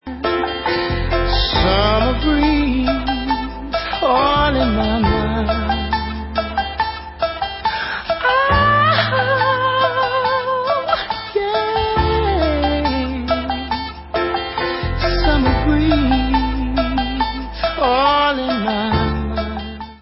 A COLLECTION OF FEEL-GOOD ANTHEMS OF SUMMER